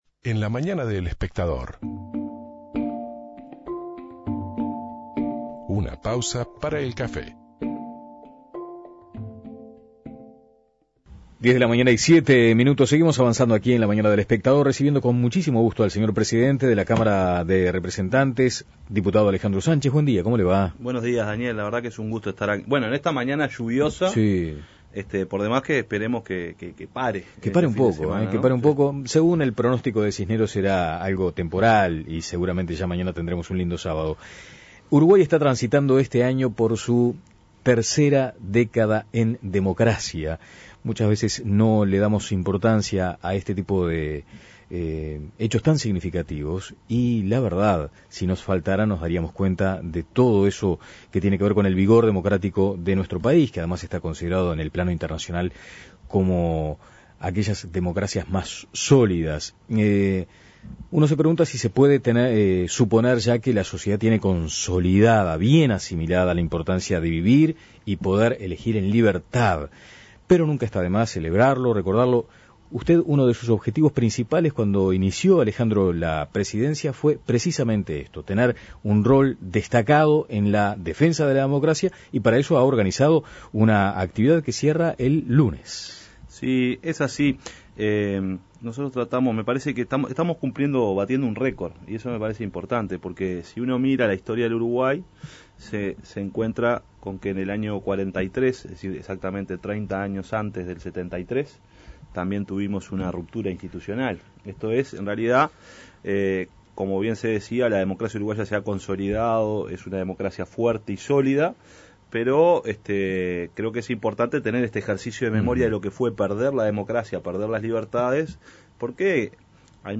Entrevista a Alejandro Sánchez